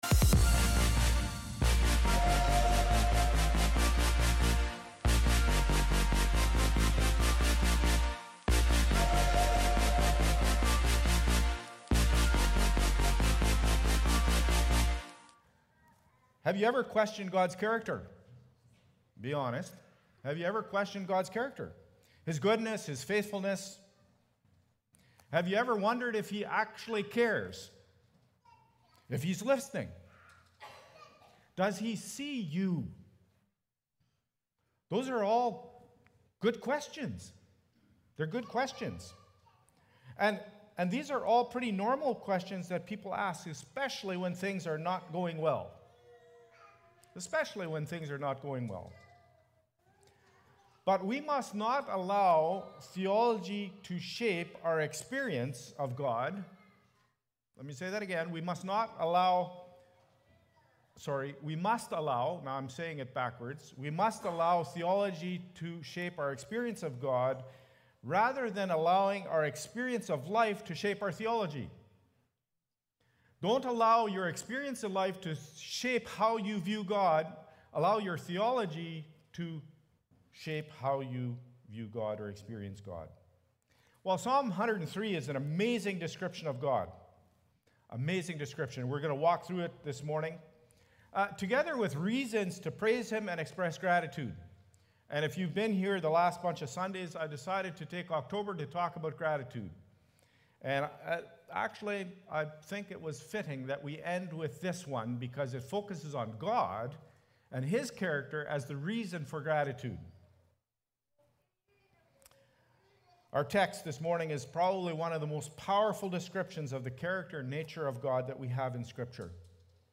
Oct-27-worship-service.mp3